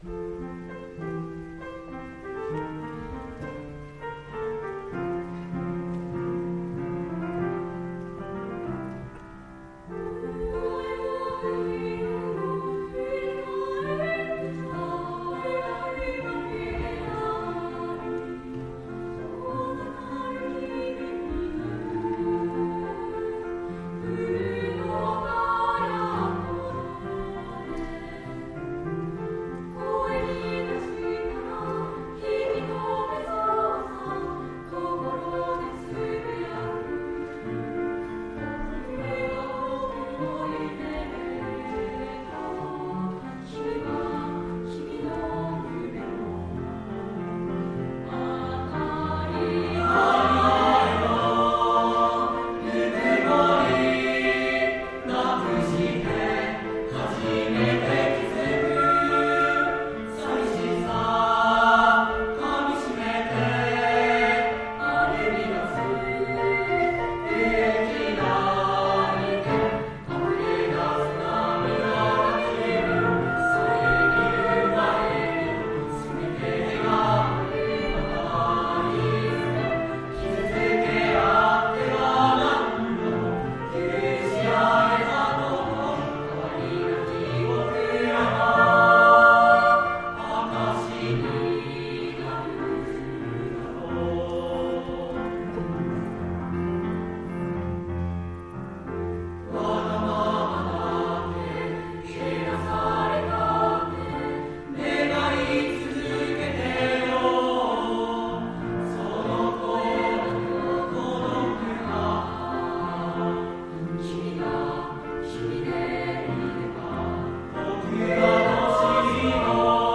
令和元年度 文化祭／合唱コンクールの歌声 【３年３・６組】
合唱コンクールでの３年３・６組の合唱を掲載しました。